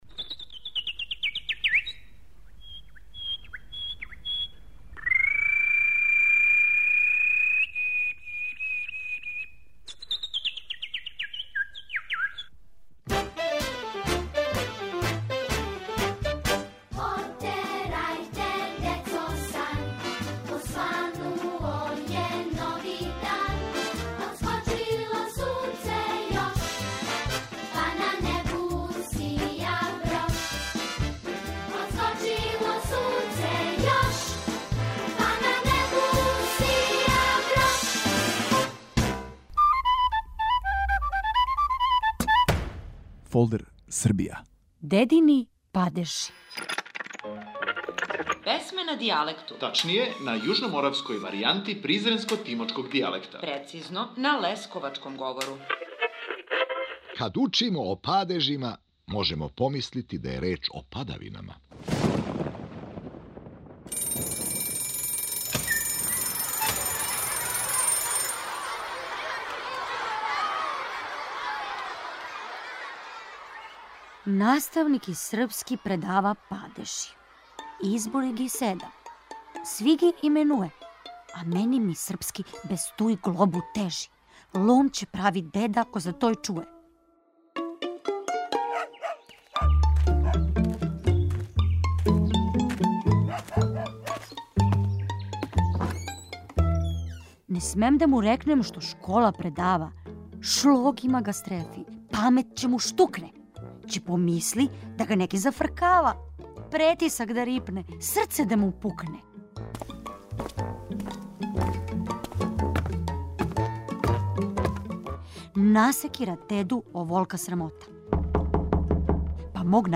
на јужноморавској варијанти призренско - тимочког дијалекта, прецизније - на лесковачком говору.